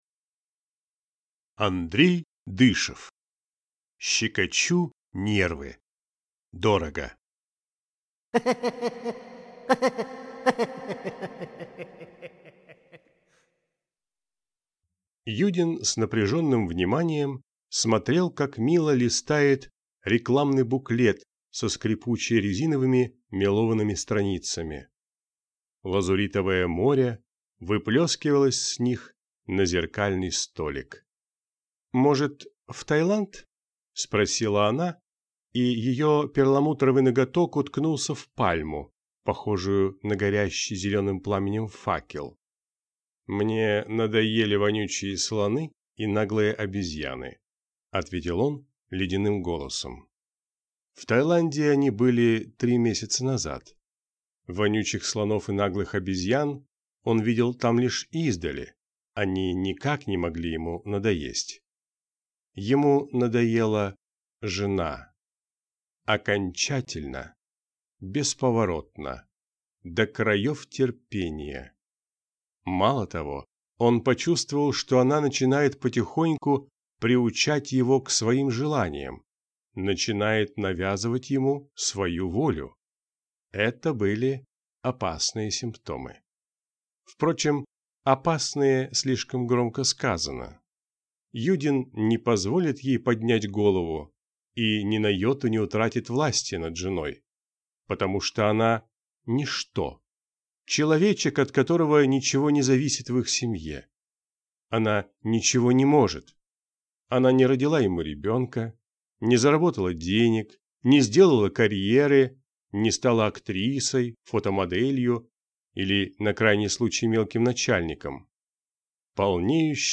Аудиокнига Щекочу нервы. Дорого | Библиотека аудиокниг